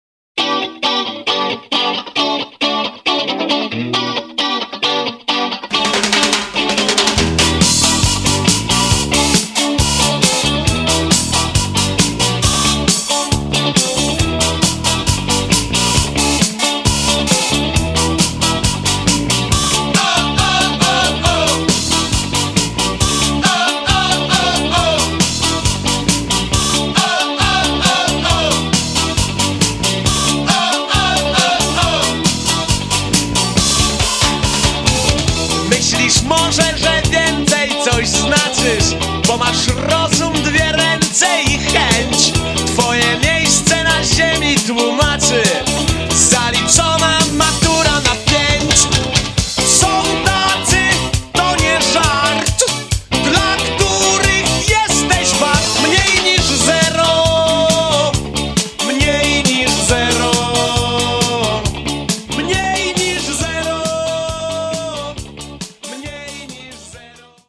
Bass Guitar
Drums
Vocals
zespół rockowy założony w 1981